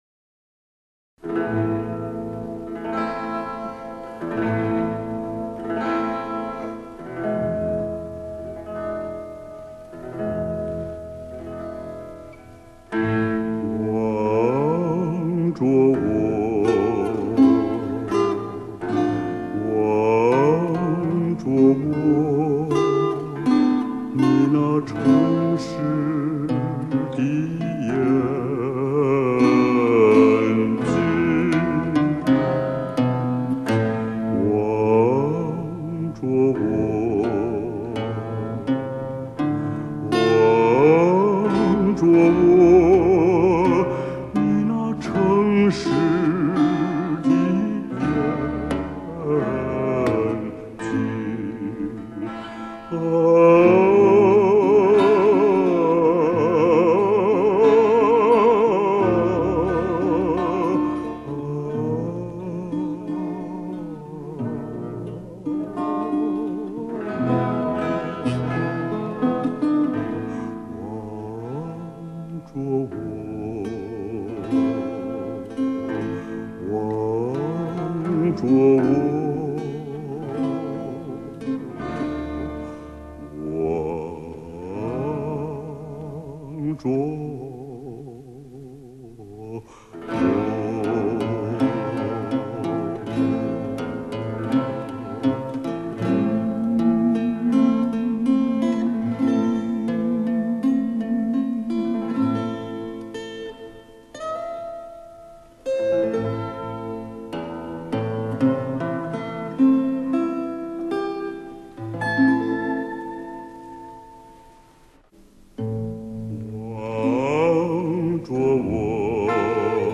【老电影原声】
插曲